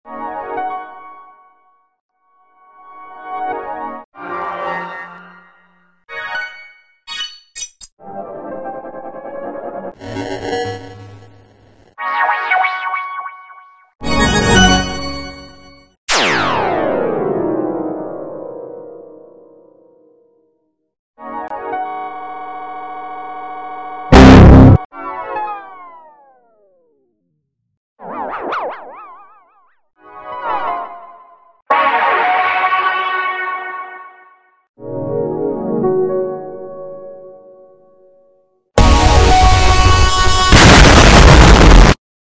19 Of My Logo Splash Sound Effects Free Download
19 of my logo splash sound variations!!